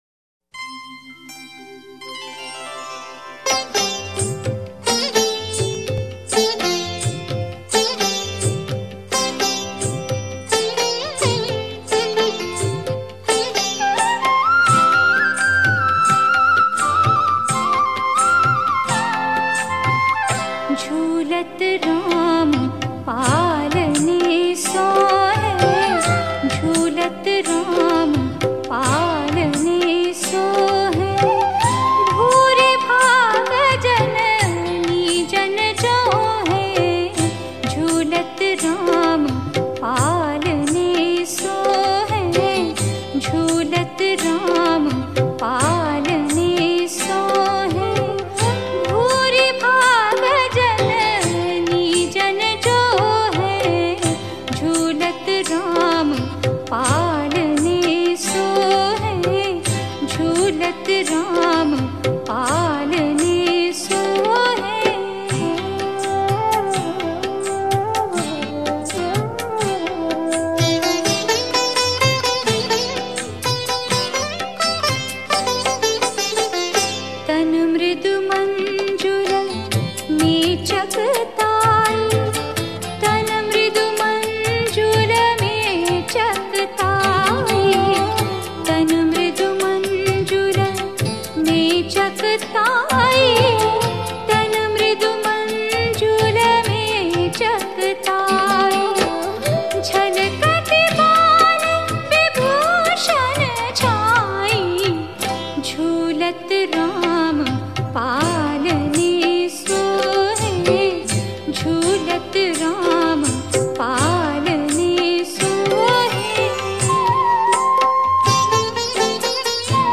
Shree Ram Bhajans